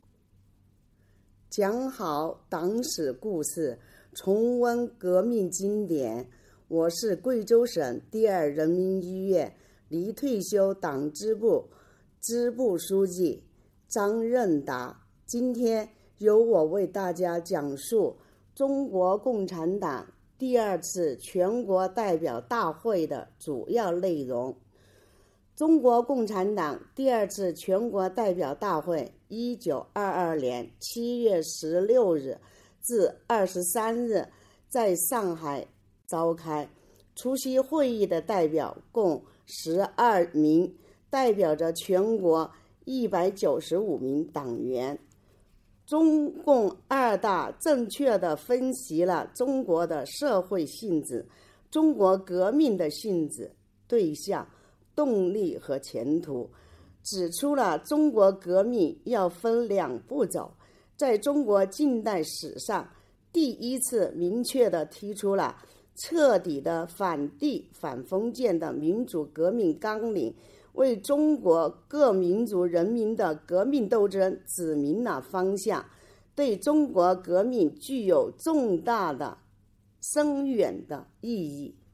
邀请院领导班子成员、支部书记、百优医护、抗疫代表、脱贫攻坚先进个人代表为讲述人，以诵读的形式，讲述党的发展历史，分享重大史事件，感悟初心使命，坚定理想信念。